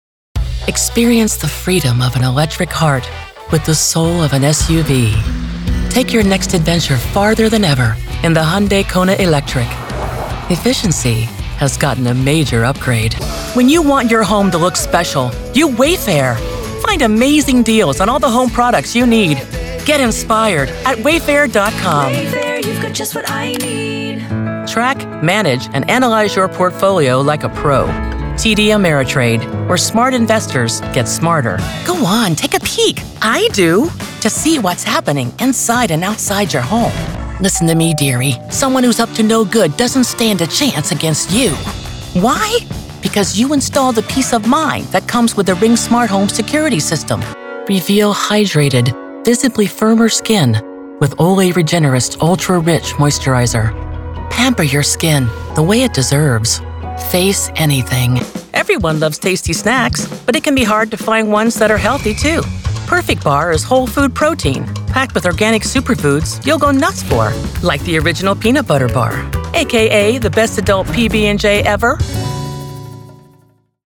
Female Voice Over, Dan Wachs Talent Agency.
Mature, Attention-Grabber, Conversational
Commercial